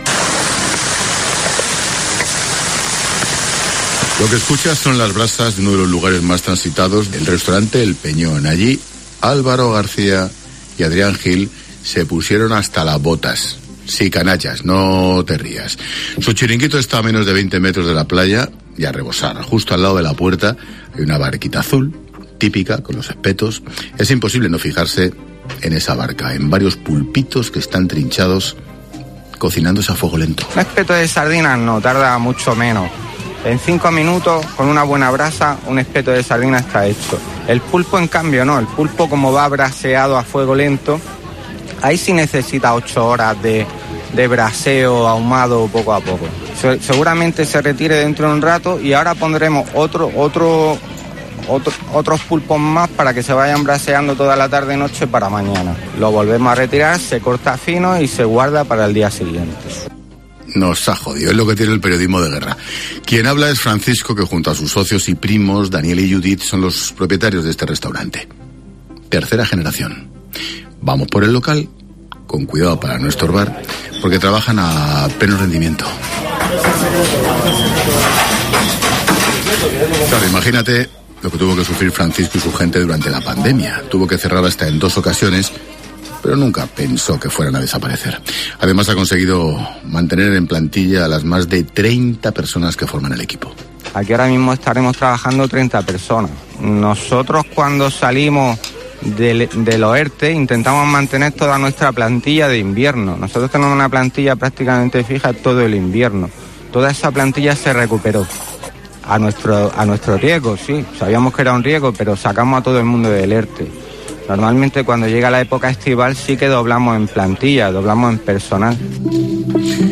Lo que escuchas son las brasas de uno de los lugares más transitados de Salobreña, el Restaurante “El Peñón”.